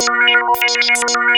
RANDOM VCF.wav